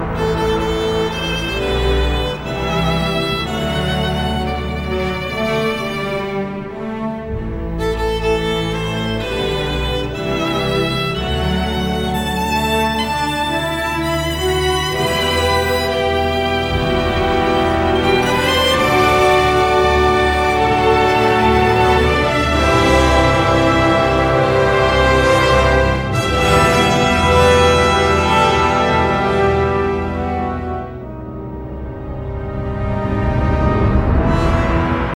• Качество: 320, Stereo
саундтреки
спокойные
без слов
скрипка
инструментальные
оркестр
трубы
эпичные